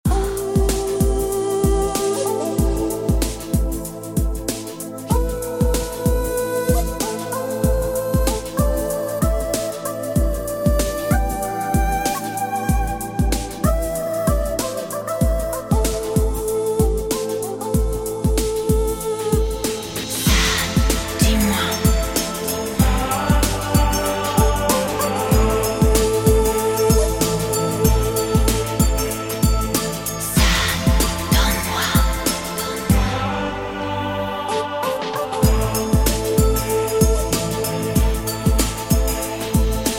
Атмосферные , New age